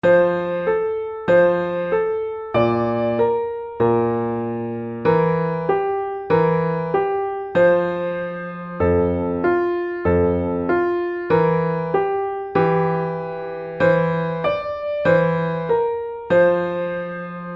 Partitura para piano.